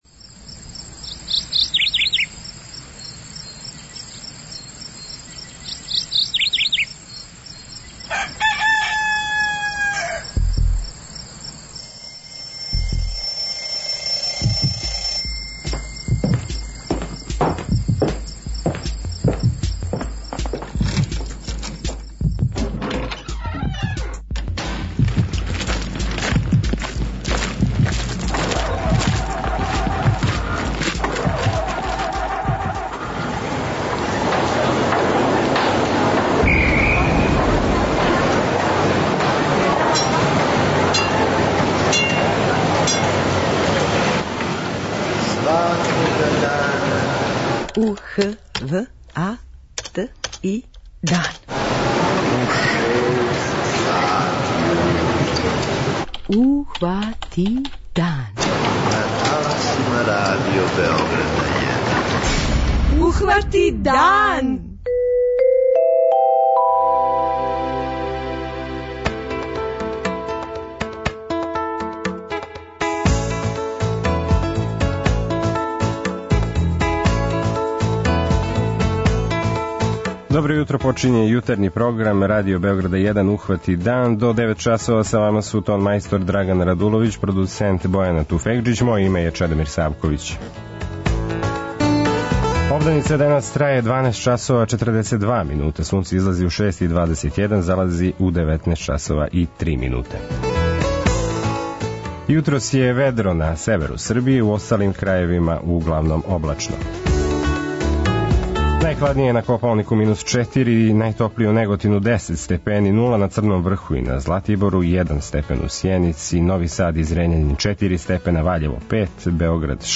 Последњег дана марта у јутарњем програму говоримо о разним темама: